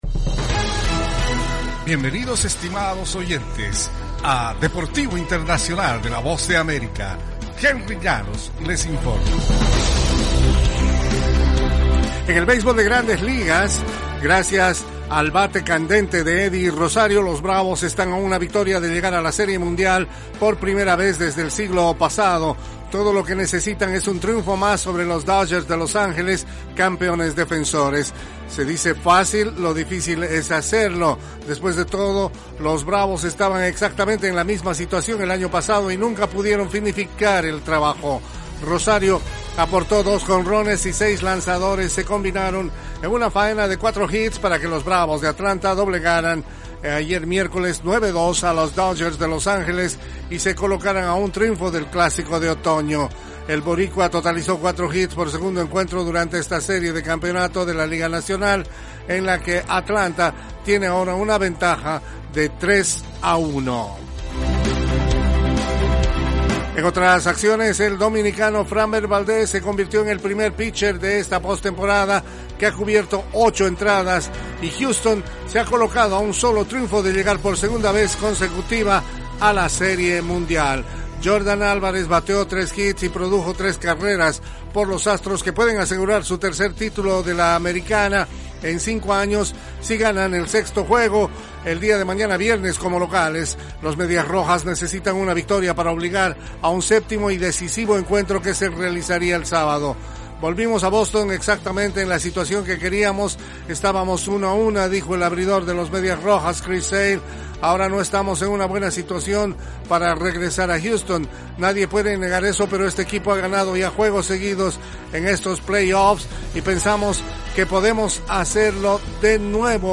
Las noticias deportivas llegann desde los estudios de la Voz de América